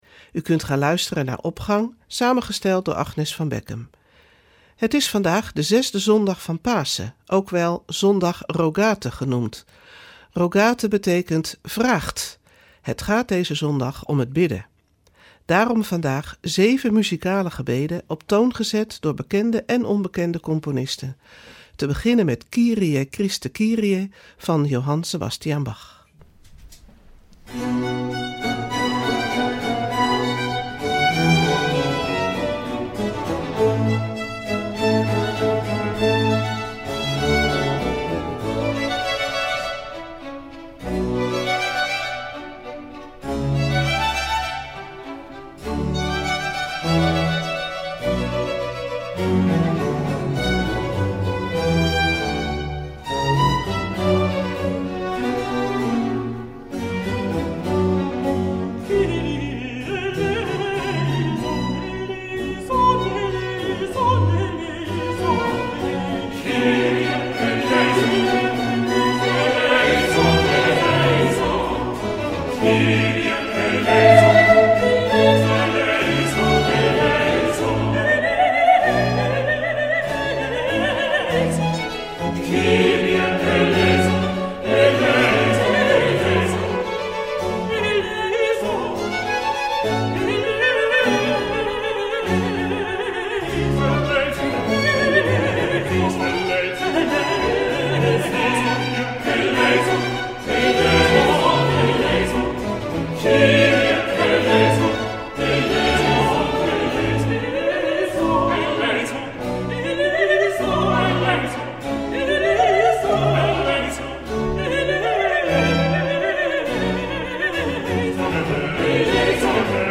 Opening van deze zondag met muziek, rechtstreeks vanuit onze studio.
Het gaat deze zondag om het bidden. Daarom vandaag zeven muzikale gebeden op toon gezet door bekende en onbekende componisten.